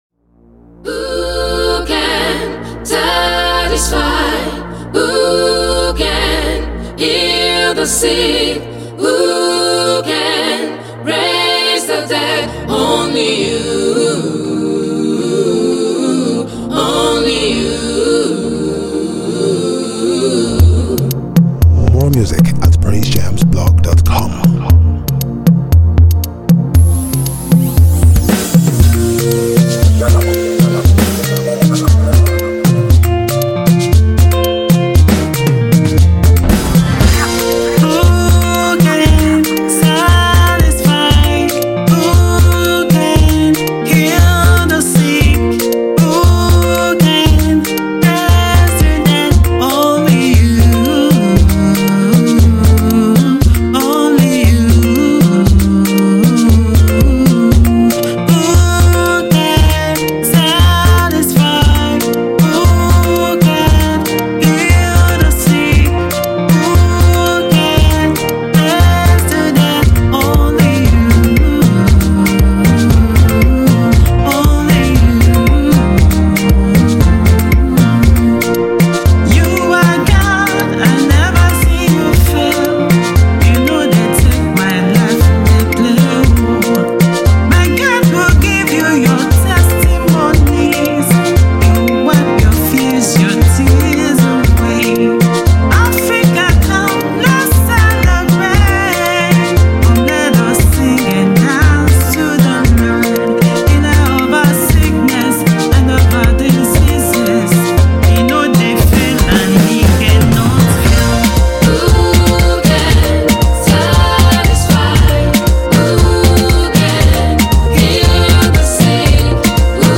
Nigerian energetic gospel artiste, singer and songwriter
gospel music
inspirational praise song